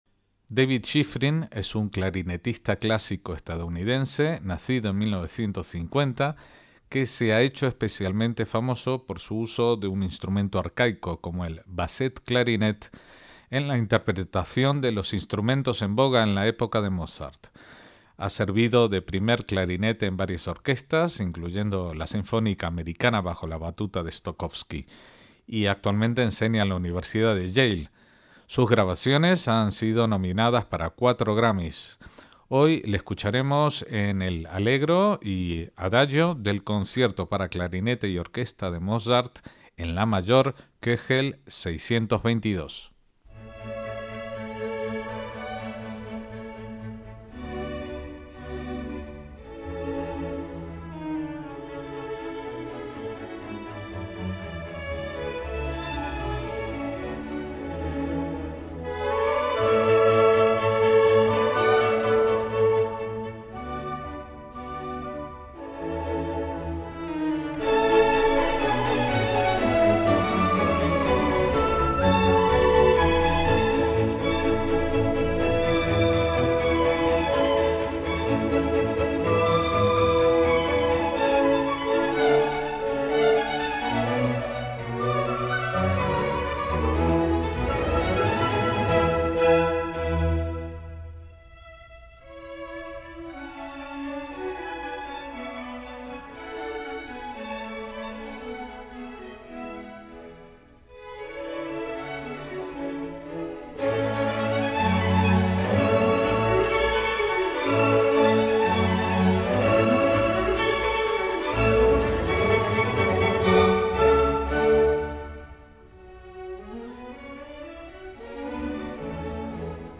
David Shifrin toca Mozart en clarinete
clarinete basset
que consta de tres movimientos del cual podremos oír los primeros dos: Allegro y Adagio.